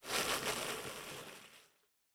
Foley Sports / Soccer
Pitch Movement Ball Rolling.wav